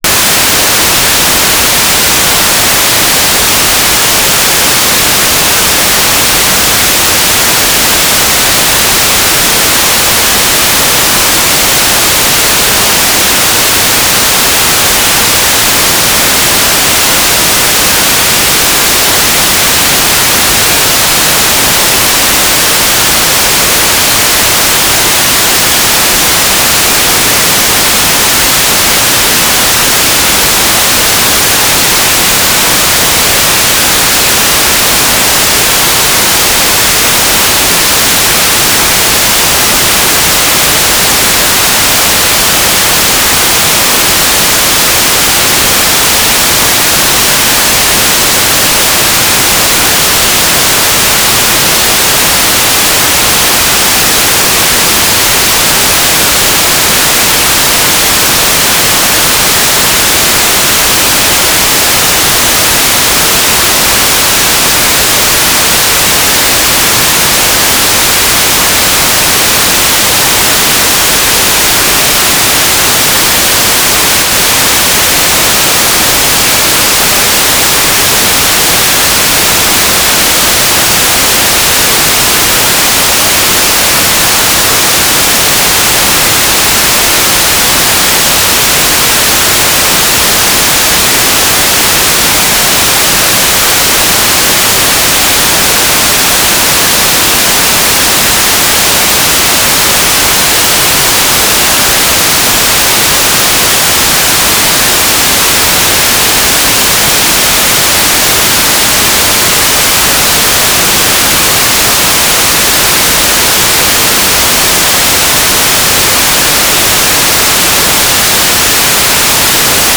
"station_name": "ISAE.Supaero UHF",
"transmitter_description": "Telemetry",
"transmitter_mode": "FSK AX.25 G3RUH",